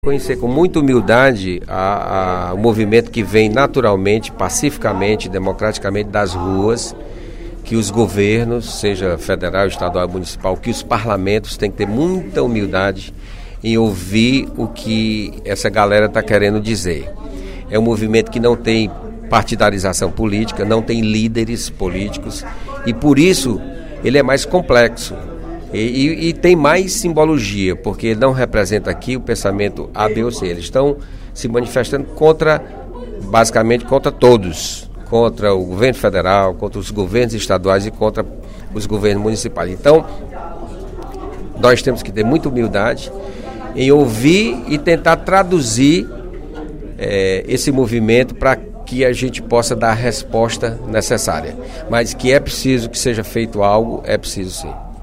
O deputado José Sarto (PSB), líder do Governo na Assembleia Legislativa, declarou, durante o primeiro expediente da sessão plenária desta terça-feira (18/06), que é preciso observar atentamente a voz da população nas manifestações que ocorrem em todo o Brasil.